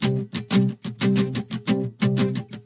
描述：爵士乐，音乐，爵士乐
Tag: 音乐 爵士乐 JA ZZY